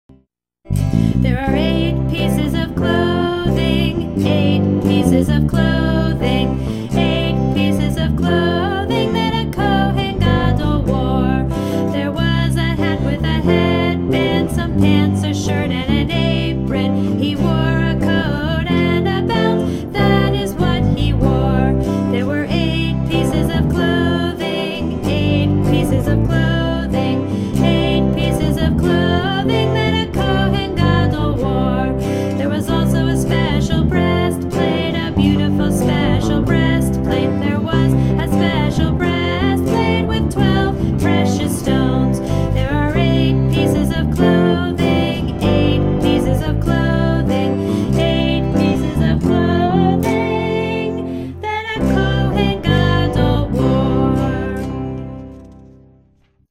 1) SONG: (tune:  The Bear Went Over the Mountain)